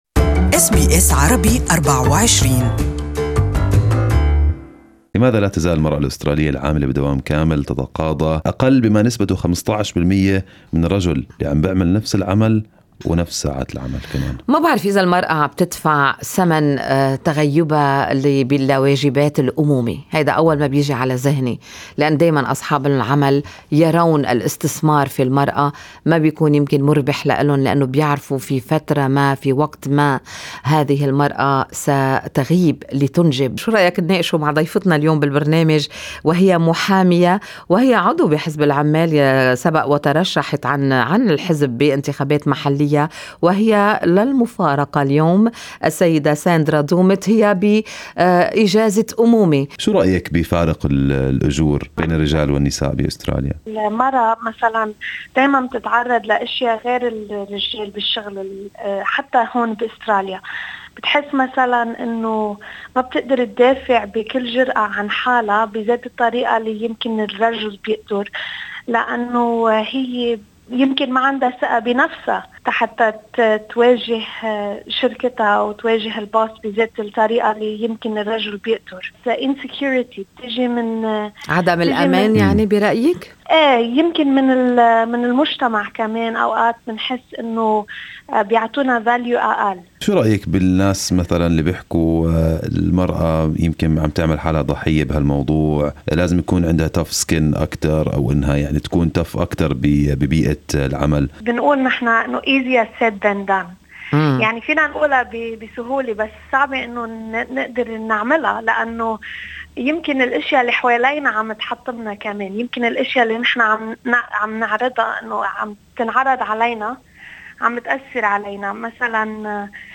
Good Morning Australia interviewed lawyer